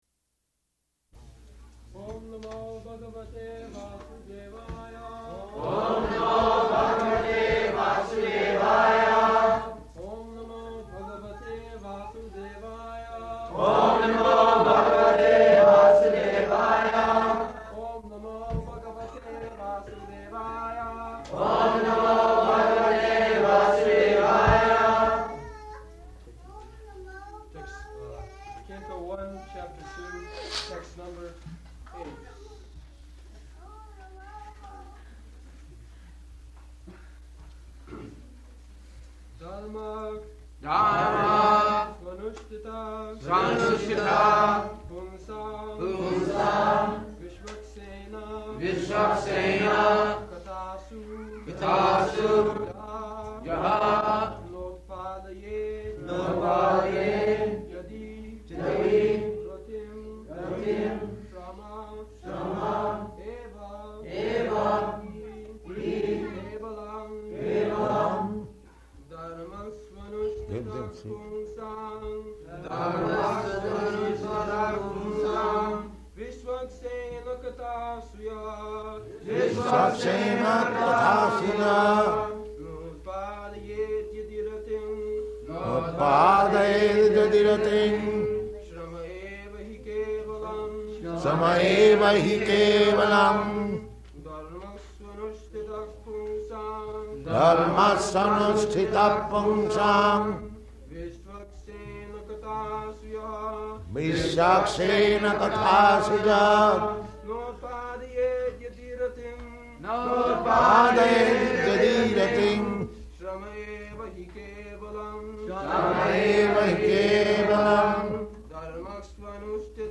Srila Prabhupada Lectures | Oh, that’s nice- That’s nice | Srimad Bhagavatam 1-2-6 | Vrindavan – In Service of Srimad Bhagavatam™ – Lyssna här – Podtail